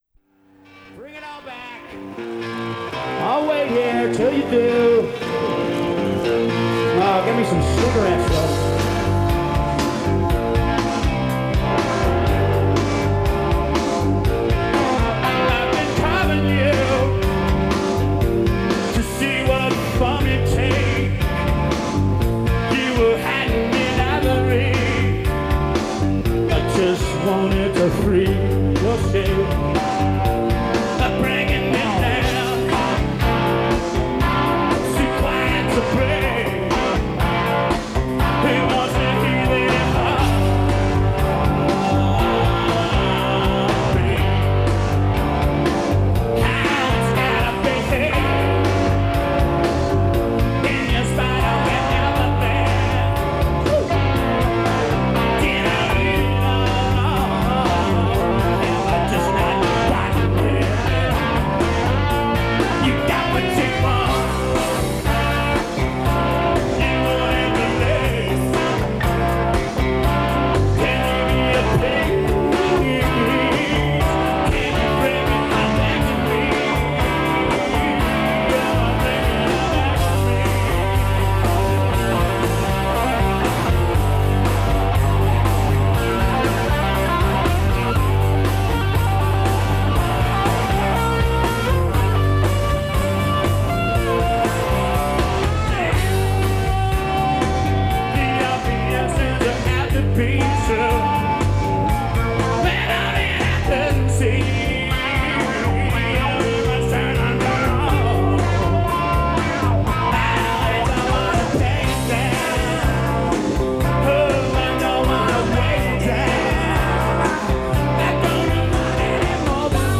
Live In 1992
Source: Radio